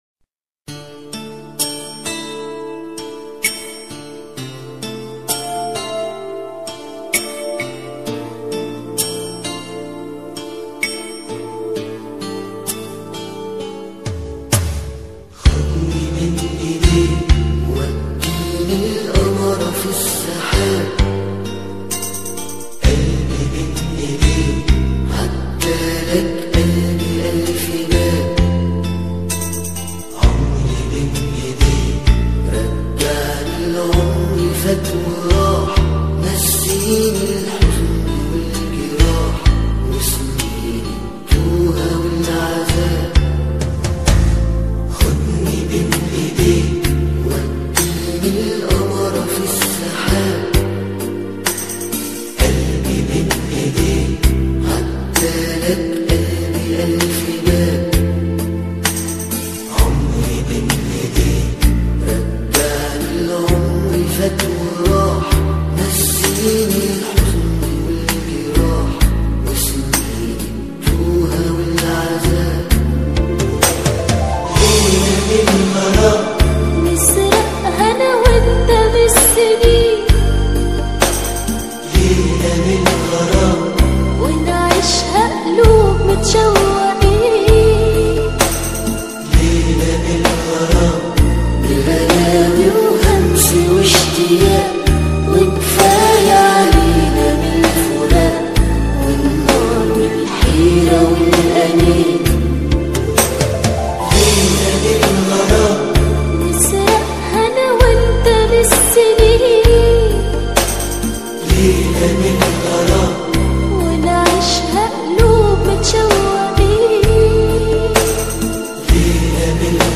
Arabskaya_muzyka_Relaks_Dlya_DushiMP3_128K.mp3